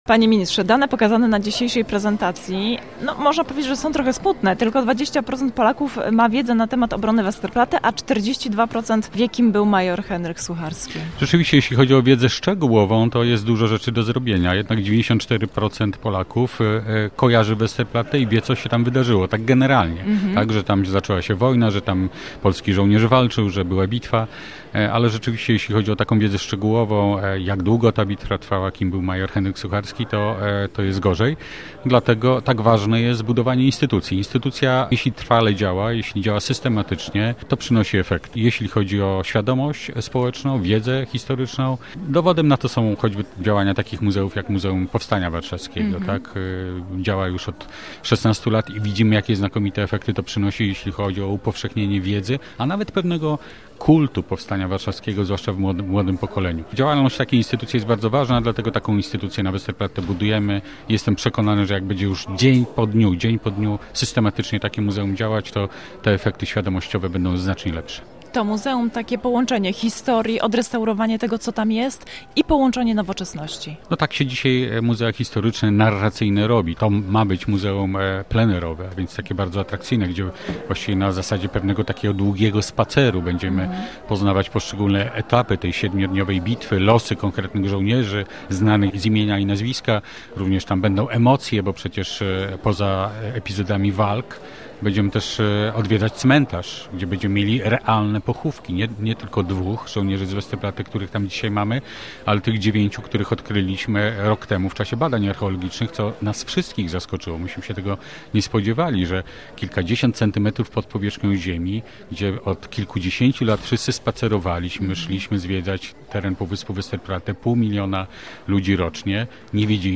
O tym z Jarosławem Sellinem, sekretarzem stanu w Ministerstwie Kultury i Dziedzictwa Narodowego, rozmawiała